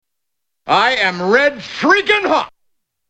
Category: Television   Right: Personal
Tags: 3rd Rock from the Sun TV sitcom Dick Solomon John Lithgow Dick Solomon clips